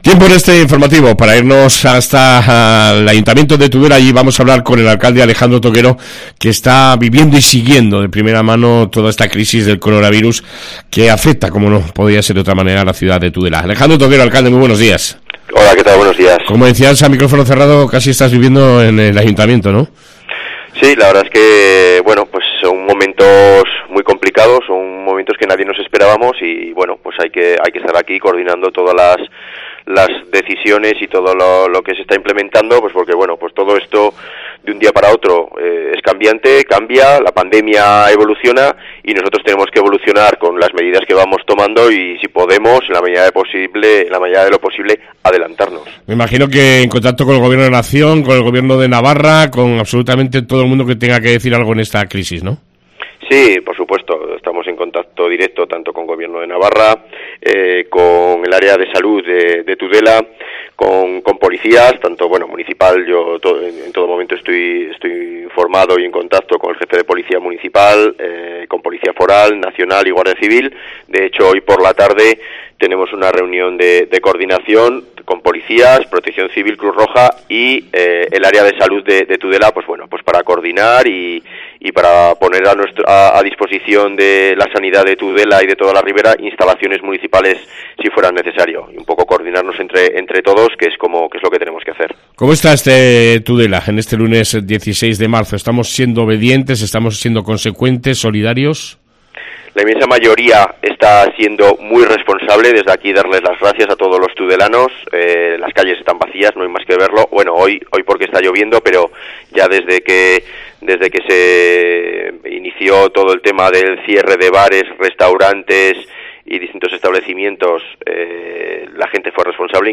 AUDIO: Ultima Hora del Coronavirus con el Alcalde de Tudela Alejandro Toquero